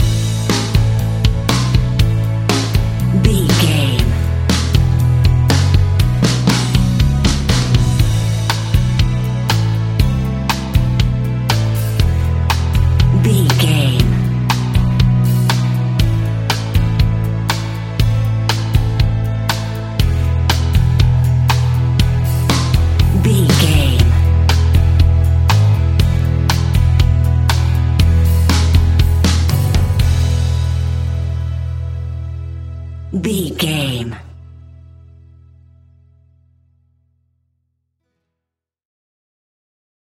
Ionian/Major
pop rock
energetic
uplifting
instrumentals
guitars
bass
drums
organ